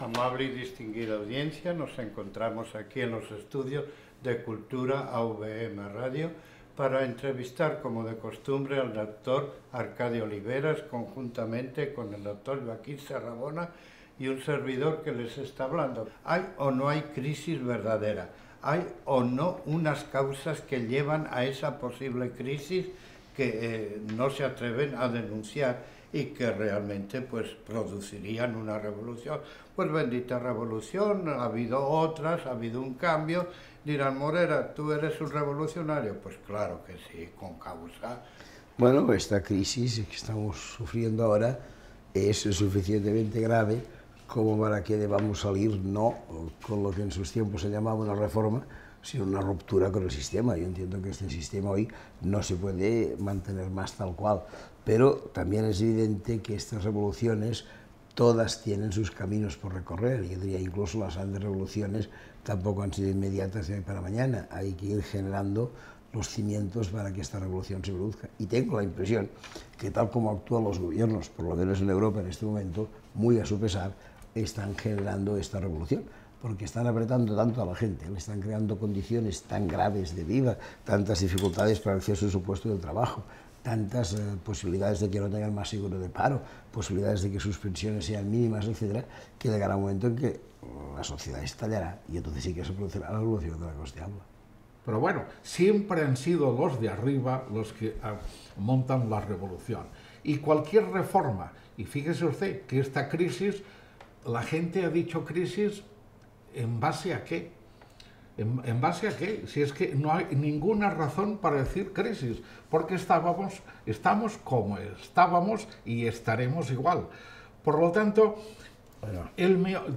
Religió